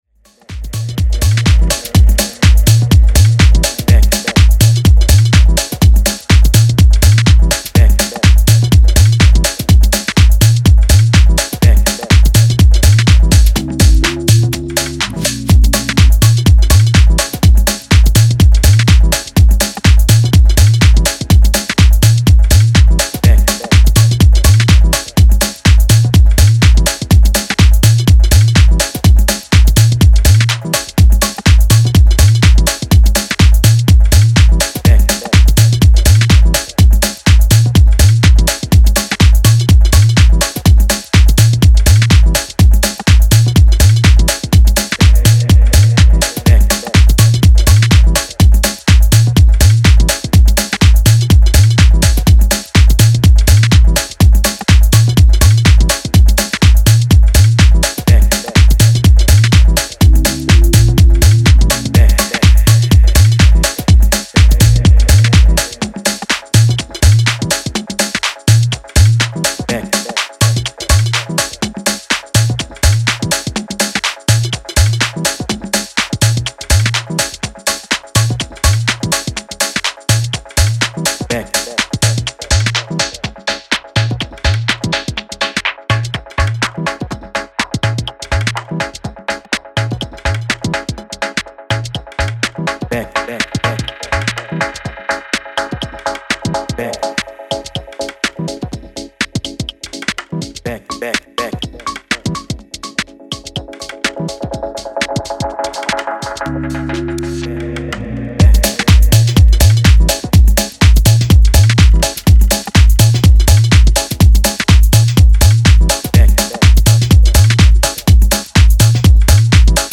Style: House